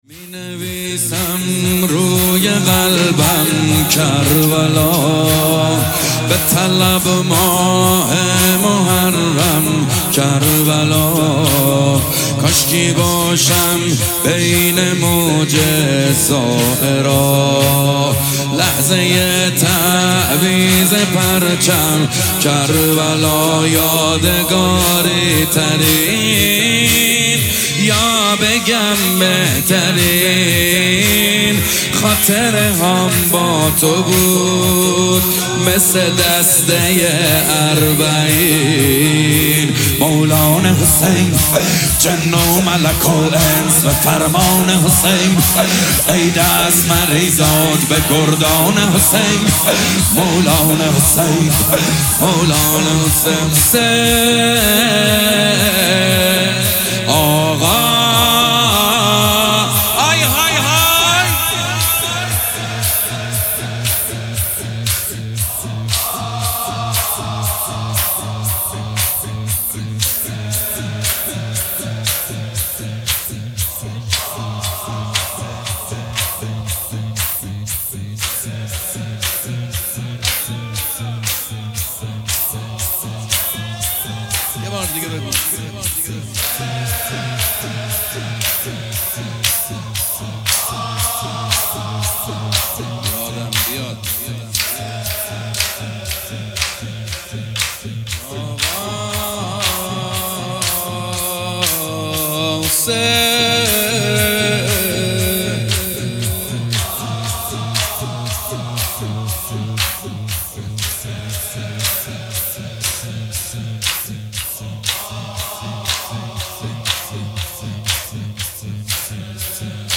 جلسه هفتگی 27 اردیبهشت 1404
مینویسم روی قلبم کربلا مداحی زمینه محمدحسین حدادیان حدادیان